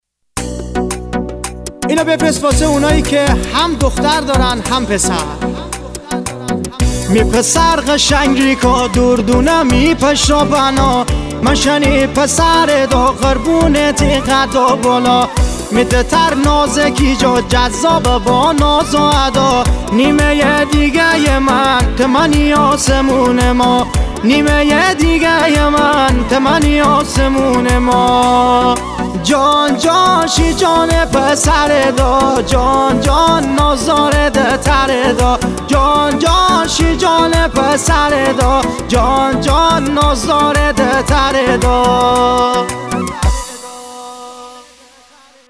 محلی